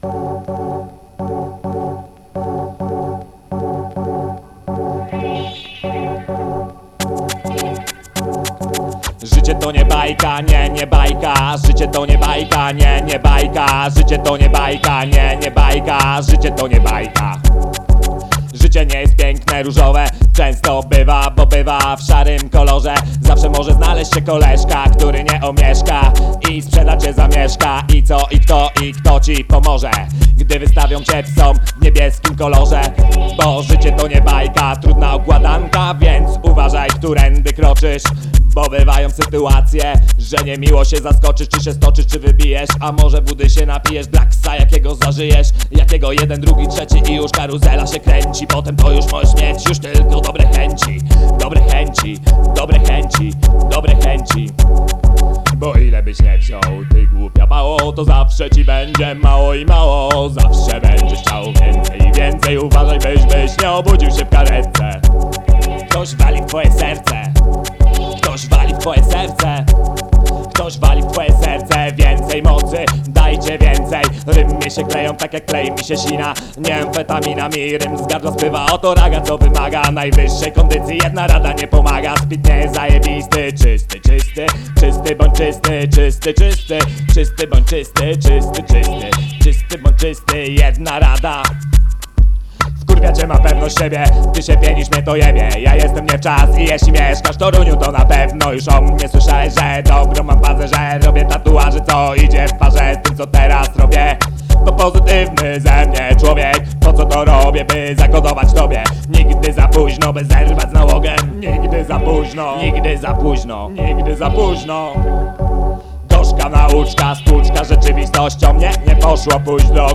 A hobby naszego dziargatora to robienie ragarapu.